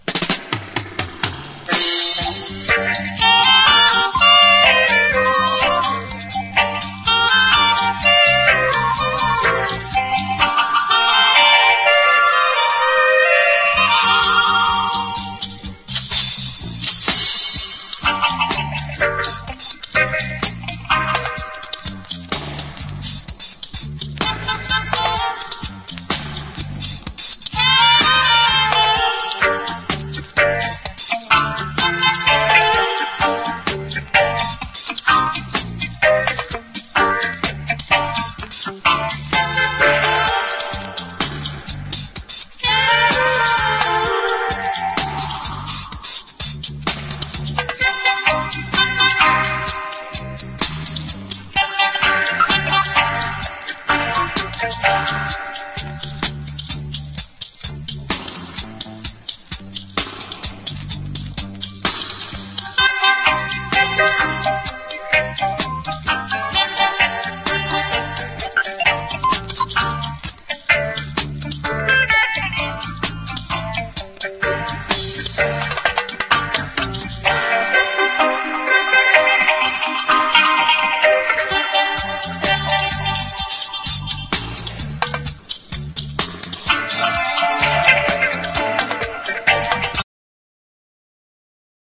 • REGGAE-SKA
DUB / UK DUB / NEW ROOTS